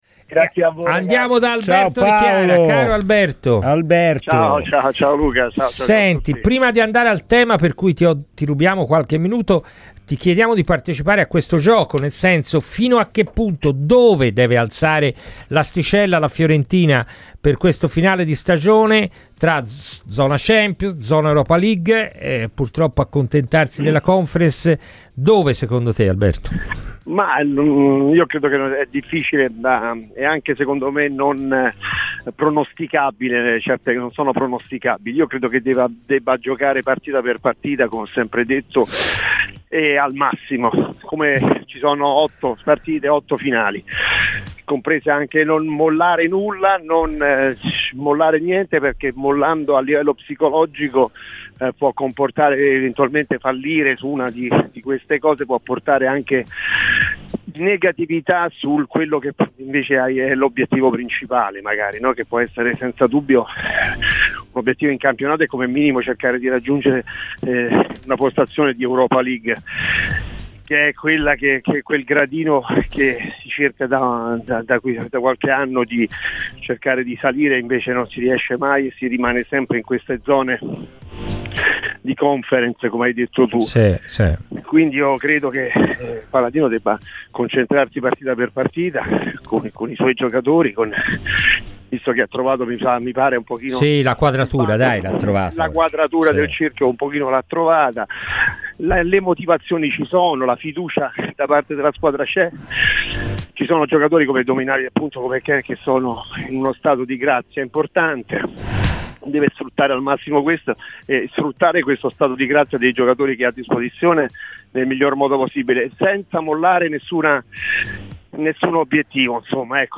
è intervenuto a Radio Firenzeviola durante la trasmissione "Palla al Centro" per parlare dell'attuale situazione della Fiorentina